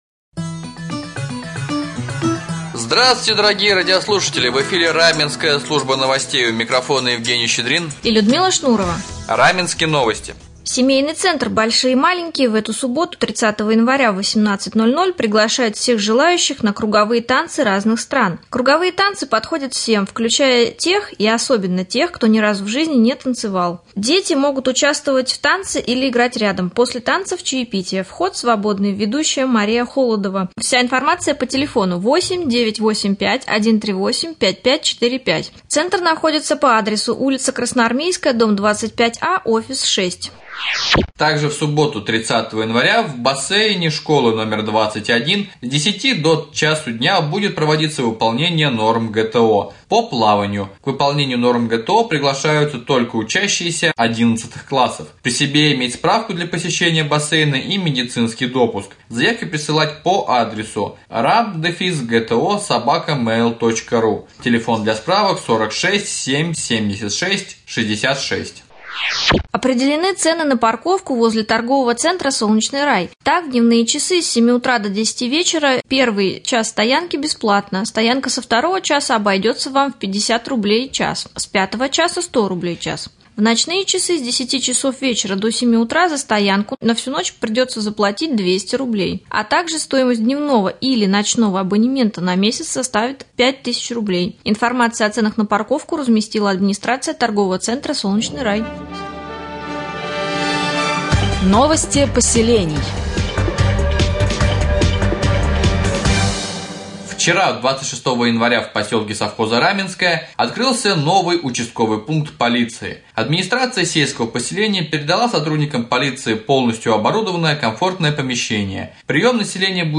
1. Прямой эфире с главой с.п.Заболотьевское 2. Новости